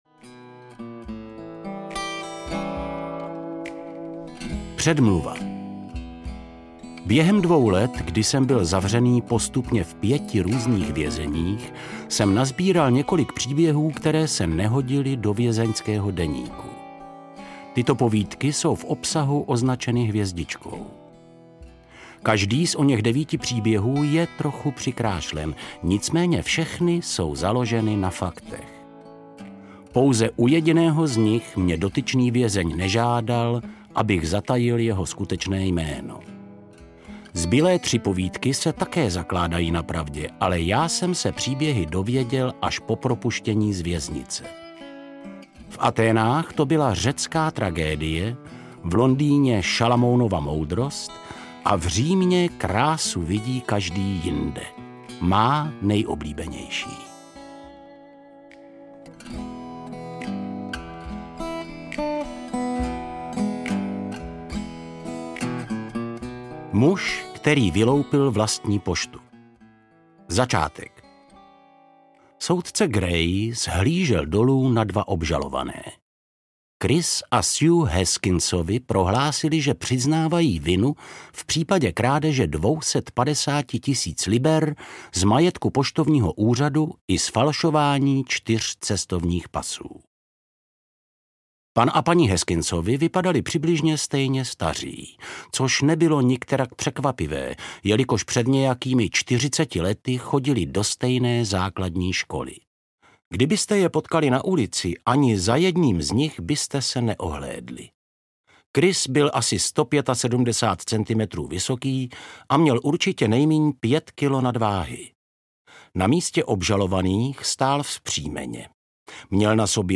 Devítiocasá kočka audiokniha
Ukázka z knihy